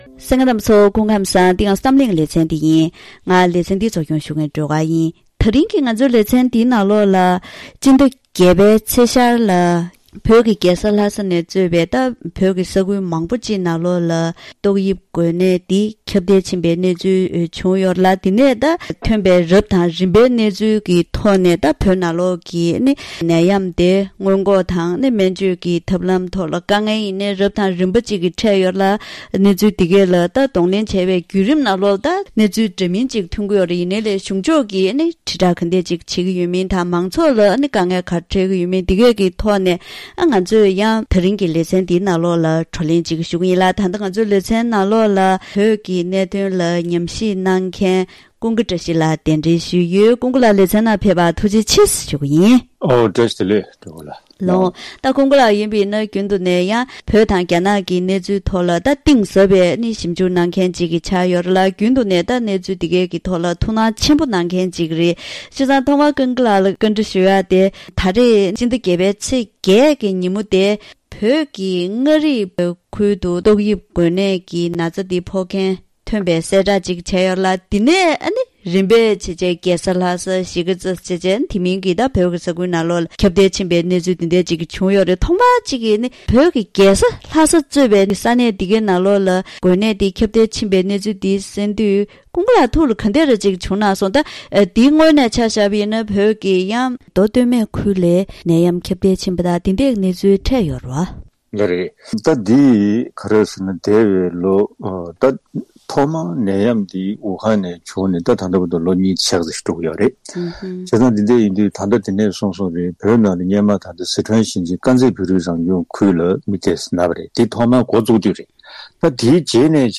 ད་རིང་གི་གཏམ་གླེང་ལེ་ཚན་ནང་ཟླ་བརྒྱད་པའི་ཚེས་ཤར་ལ་བོད་ཀྱི་རྒྱལ་ས་ལྷ་སས་གཙོས་པའི་ས་གནས་མང་པོའི་མང་ཏོག་དབྱིབས་འགོས་ནད་ཁྱབ་གདལ་ཆེན་པོ་ཕྱིན་ཏེ་སྨན་བཅོས་ཡག་པོ་མ་བྱུང་བ་དང་ཉིན་རེའི་འཚོ་བའི་དགོས་མཁོ་མི་གདེང་བ་སོགས་མང་ཚོགས་ཁྲོད་ཕྱོགས་མང་པོ་ནས་དཀའ་ངལ་ཆེན་པོ་འཕྲད་བཞིན་ཡོད་པས།